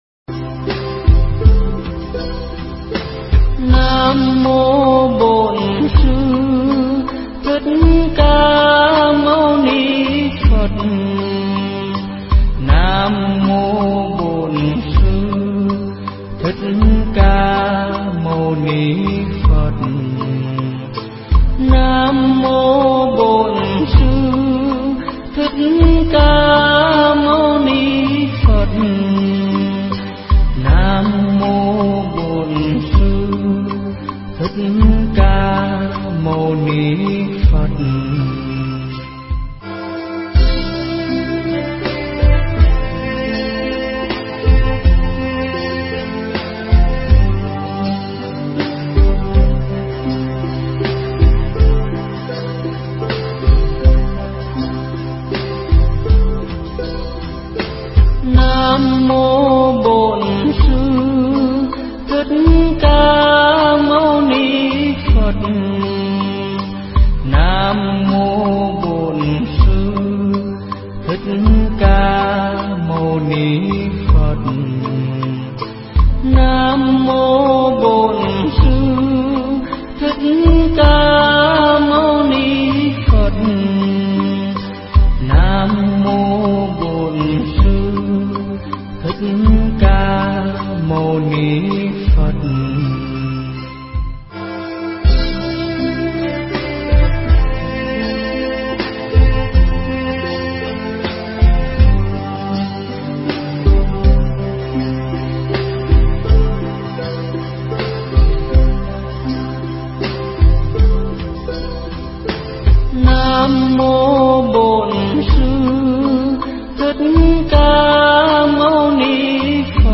Nghe Mp3 thuyết pháp 7 Tài Sản Quý Giá